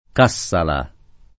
DOP: Dizionario di Ortografia e Pronunzia della lingua italiana
Cassala → Kassalā